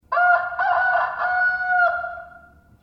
rooster.8ddd0c9c9284a6e82b4a.mp3